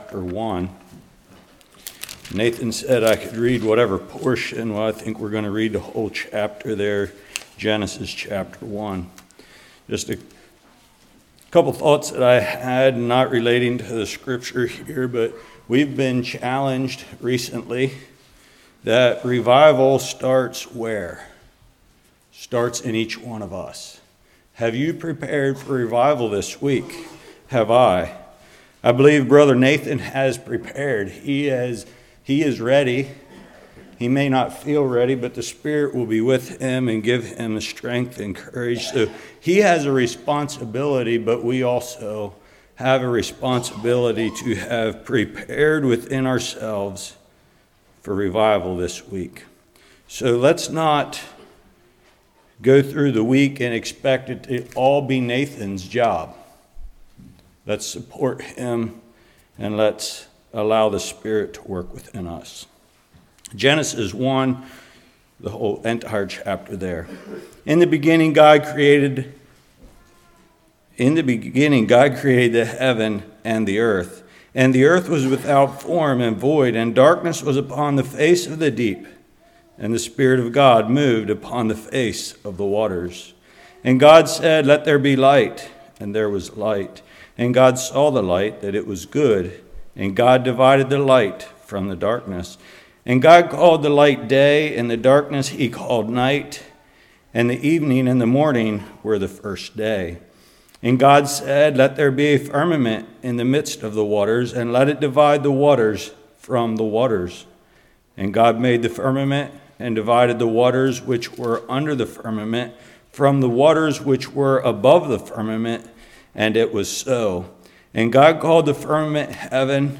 Genesis 1:1-31 Service Type: Revival Foundations in Genesis